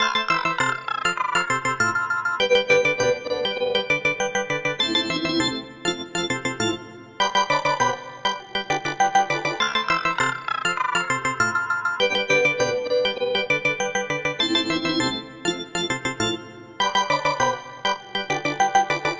嘻哈风琴 100
Tag: 100 bpm Hip Hop Loops Organ Loops 3.23 MB wav Key : G